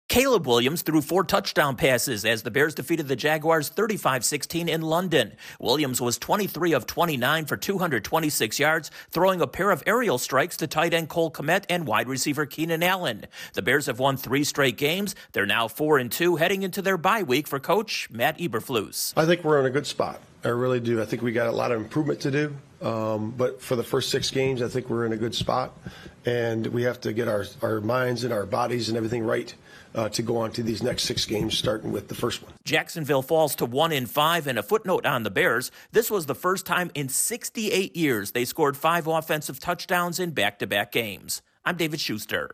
The Bears continue their winning streak. Correspondent